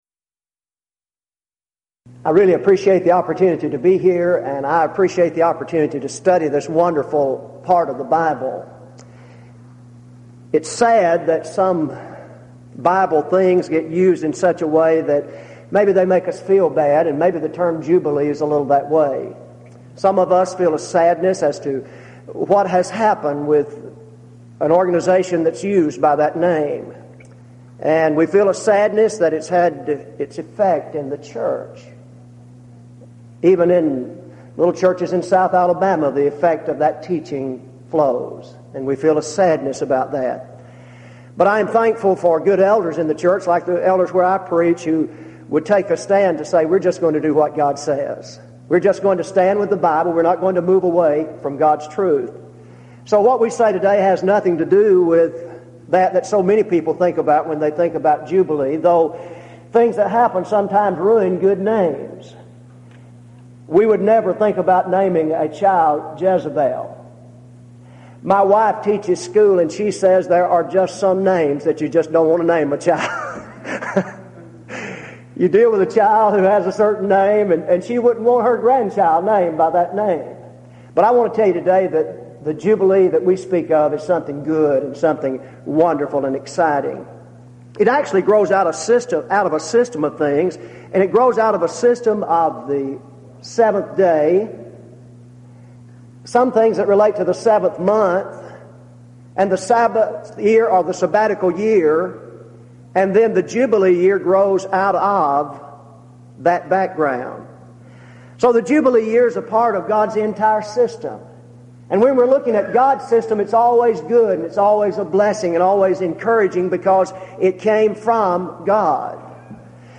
Event: 1998 East Tennessee School of Preaching Lectures Theme/Title: Studies in the Book of Leviticus
lecture